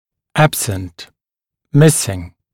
[‘æbsənt], [‘mɪsɪŋ][‘эбсэнт]отсутствующий